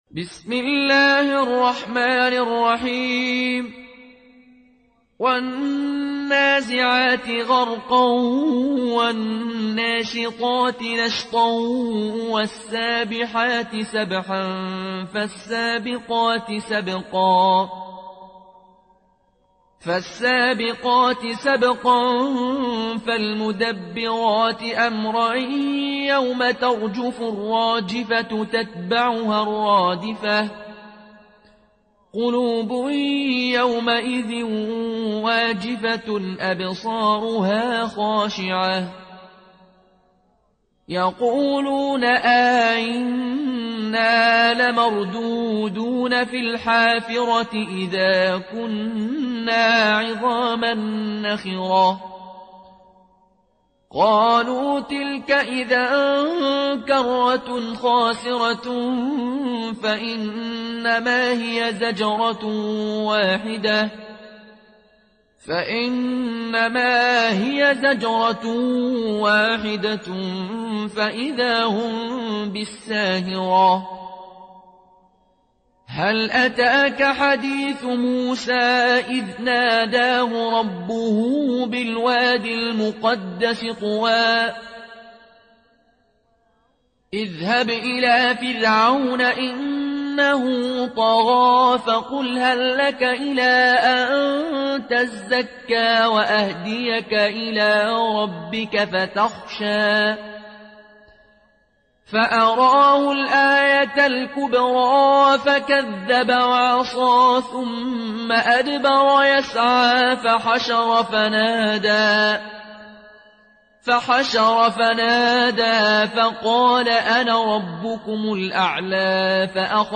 برواية قالون عن نافع